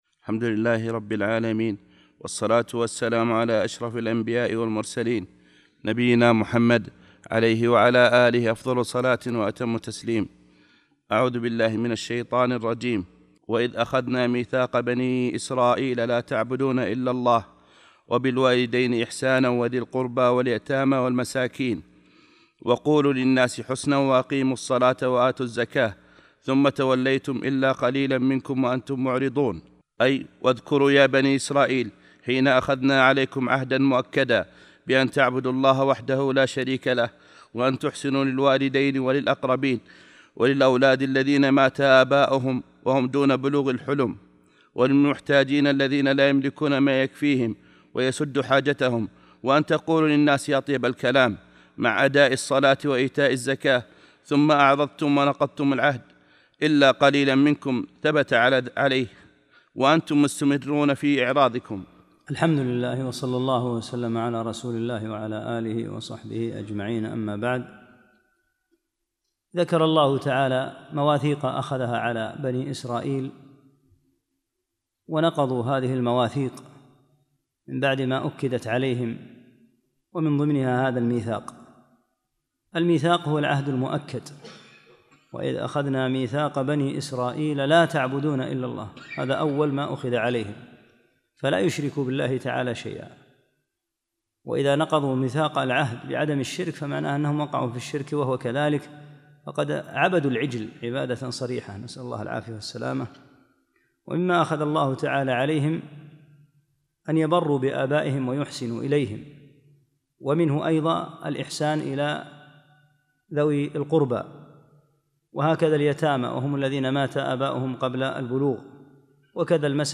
9- الدرس التاسع